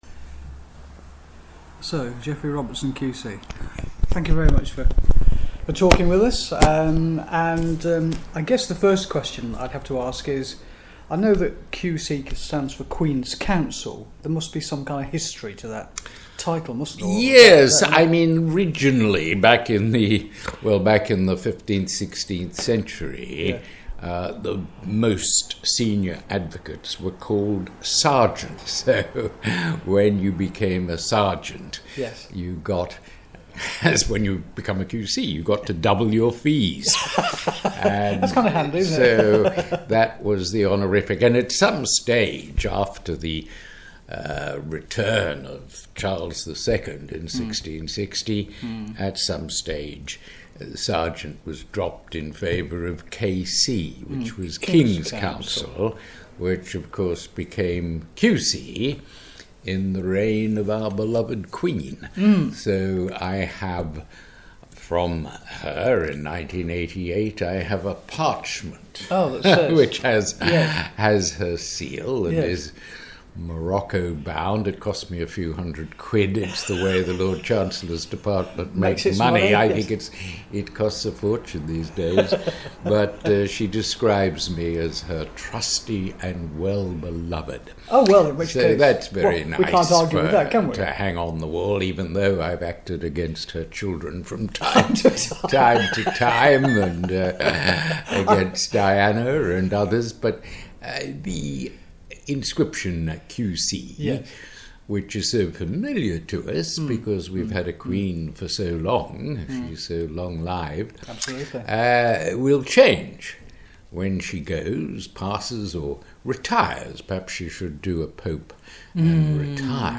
It was a privilege to be able to interview renowned jurist and lawyer Geoffrey Robertson QC in his Doughty Street Chambers a couple of months ago.